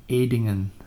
Dutch pronunciation